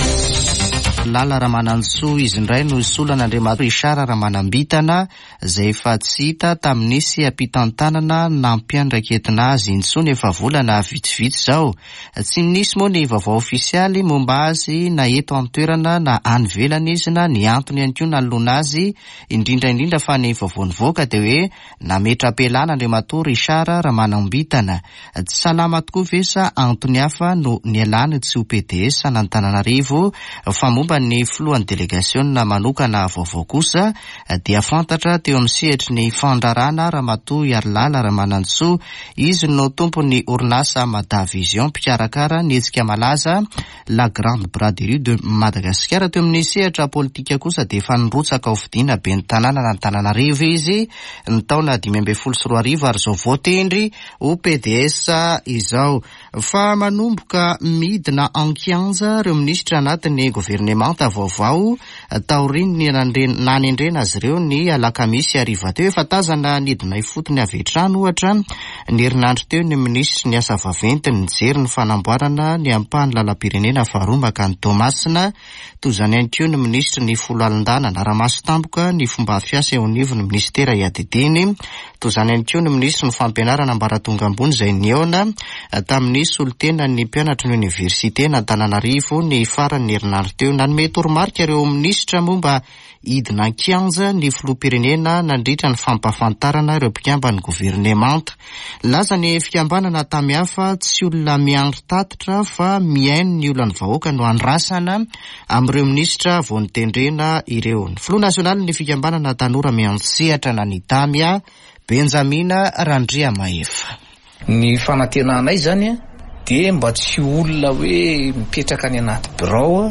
[Vaovao maraina] Talata 27 aogositra 2024